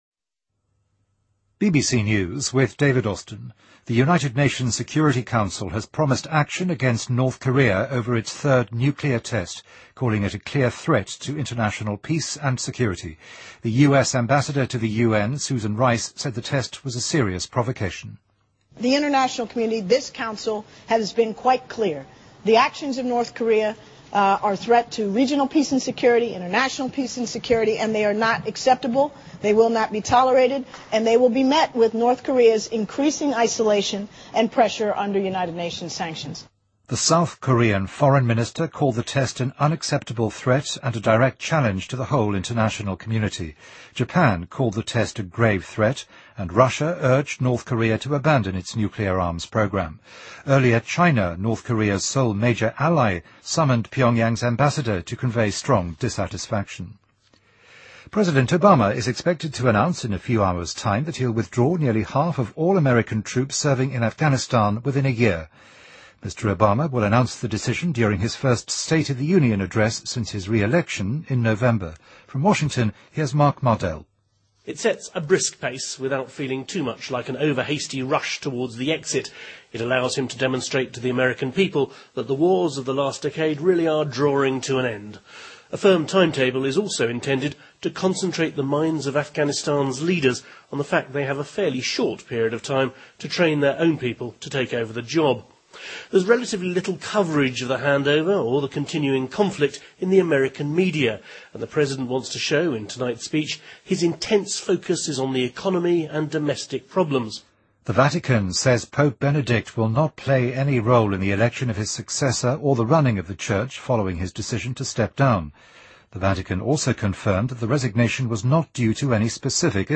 BBC news,联合国安理会承诺对朝鲜进行第三次核试验采取行动